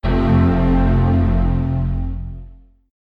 Mac OS X (later) - Big Sur Startup.mp3